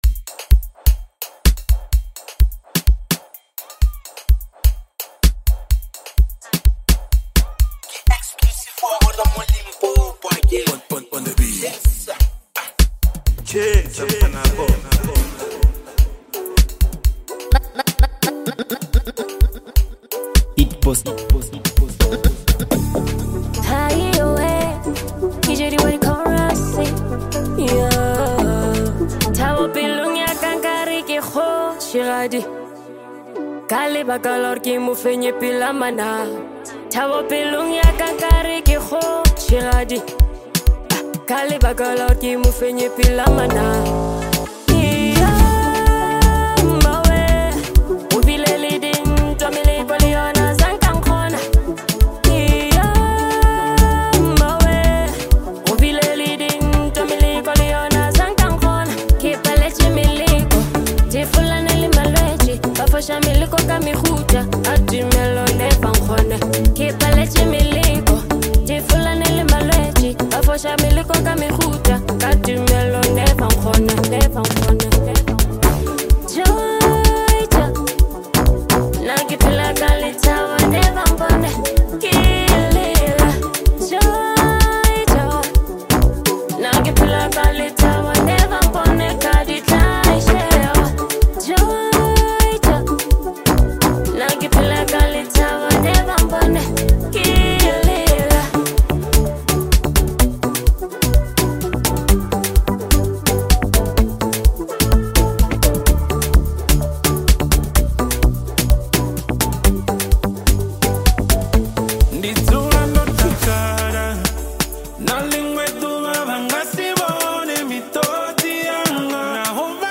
Lekompo